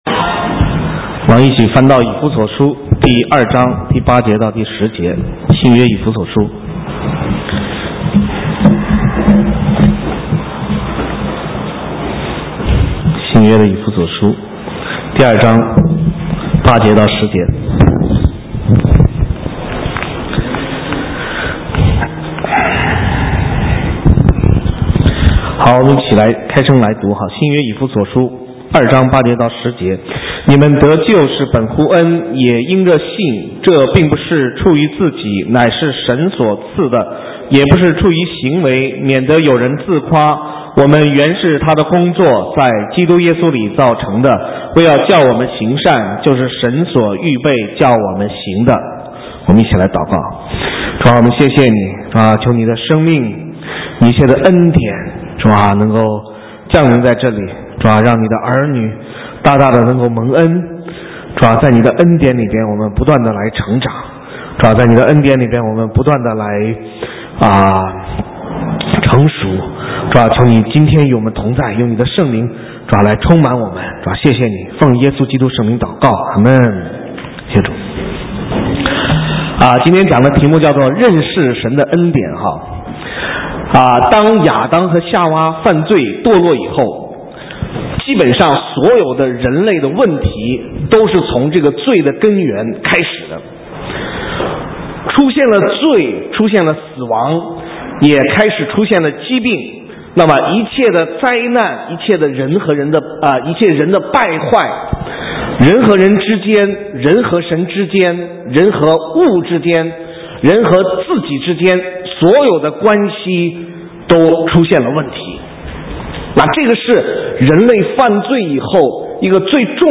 神州宣教--讲道录音 浏览：认识神的恩典 (2010-12-12)